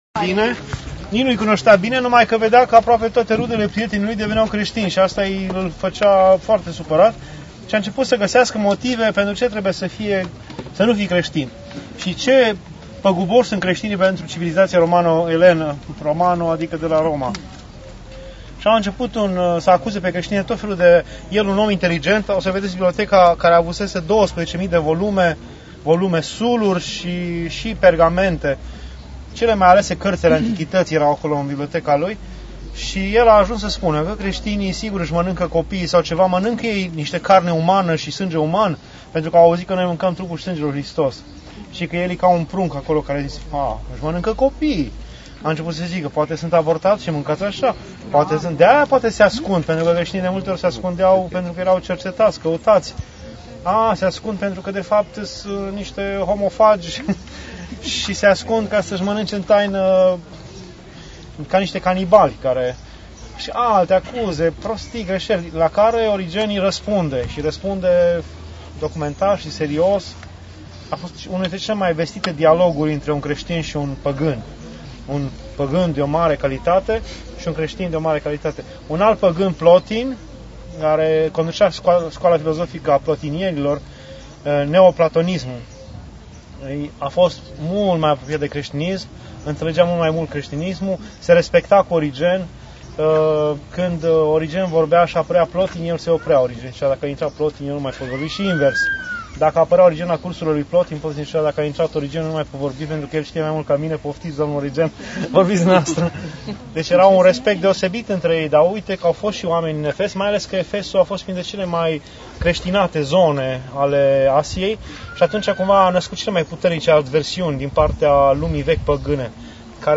Pelerinaj 2 Turcia, Grecia